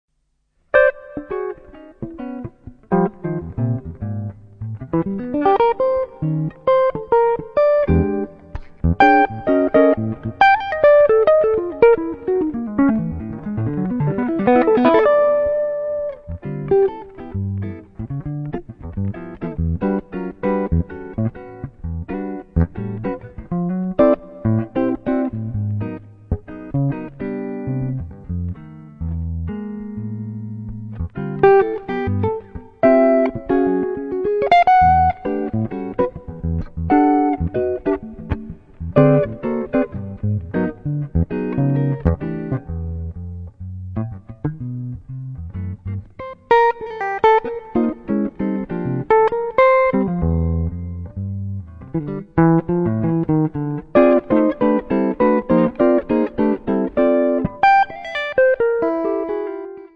chitarre